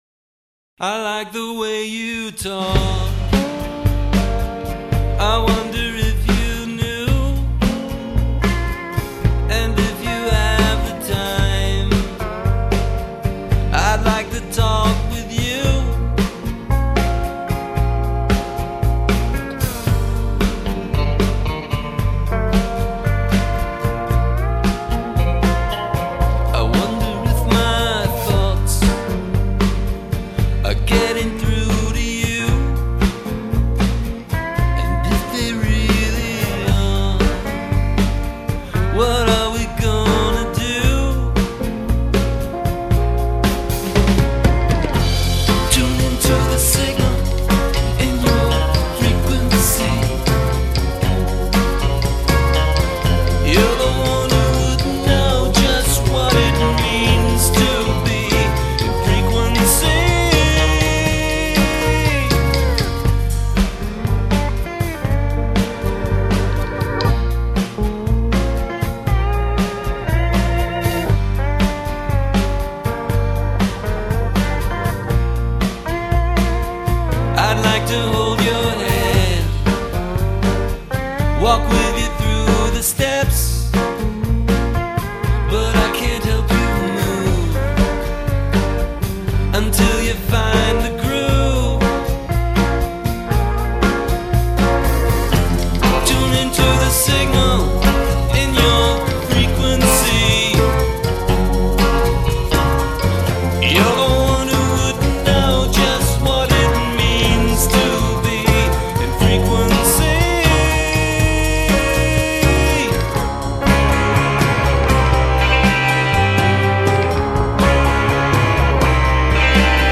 pop/rock band
Bluesy, hypnotic, melodic hooks and thoughtful lyrics...